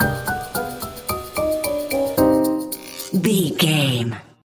Aeolian/Minor
percussion
flute
orchestra
piano
circus
goofy
comical
perky
Light hearted
quirky